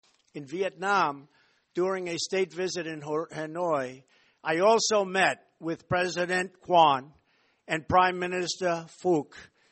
Khi nghe lại đoạn video phát biểu, phóng viên VOA tiếng Việt thấy nhà lãnh đạo Mỹ phát âm đúng tên của ông Phúc.
Nghe Tổng thống Mỹ Donald Trump đọc tên ông Phúc